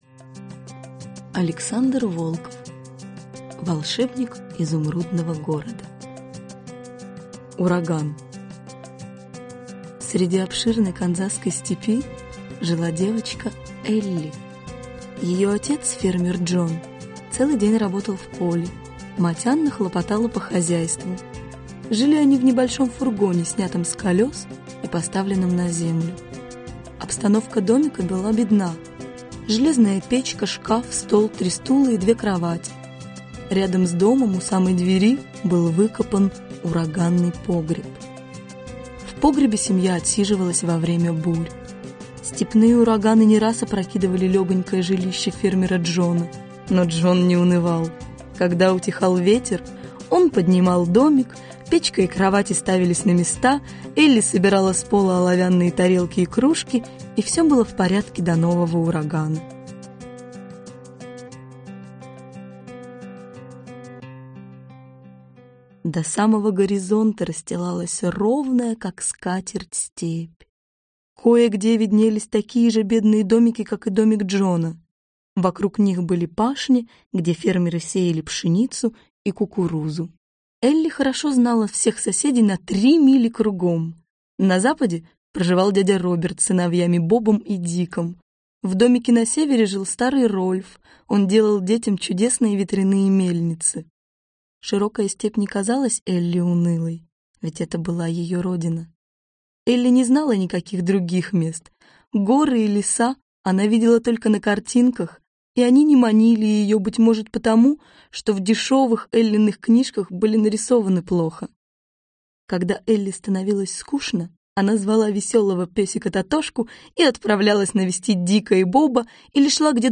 Аудиокнига Волшебник Изумрудного города - купить, скачать и слушать онлайн | КнигоПоиск